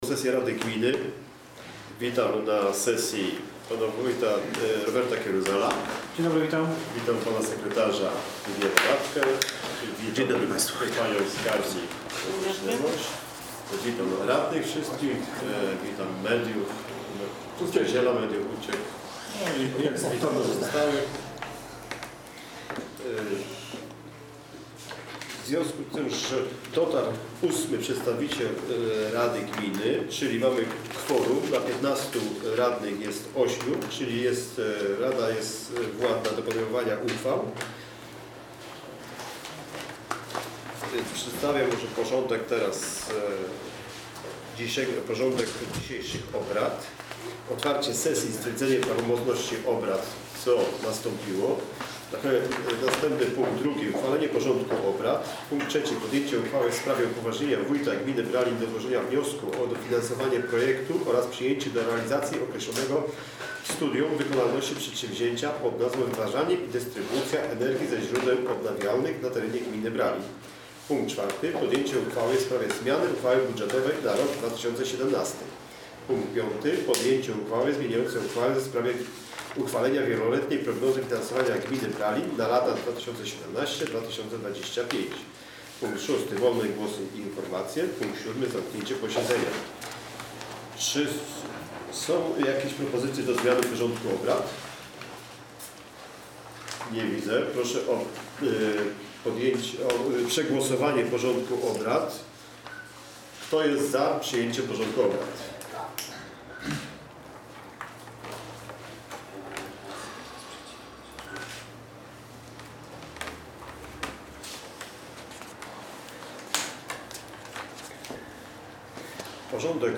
Nagranie: 50 sesja rady - 16 sierpnia 2017 r.